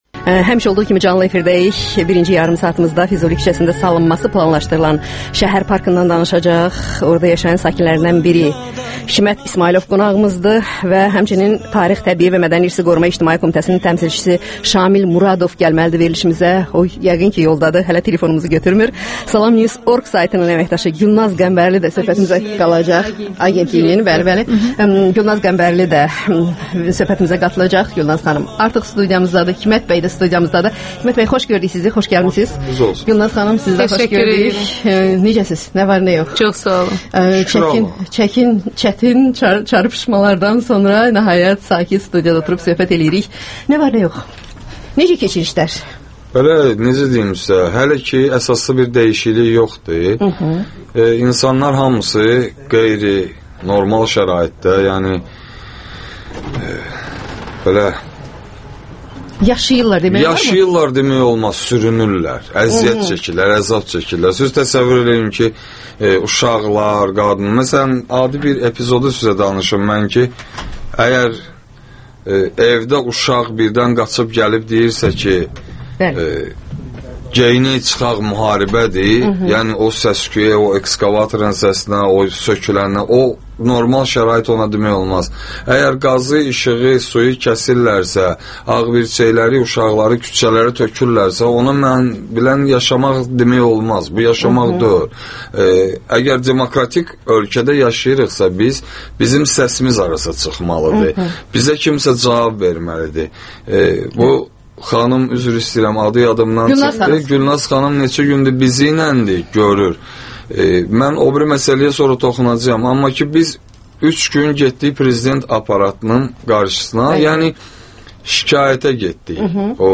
söhbət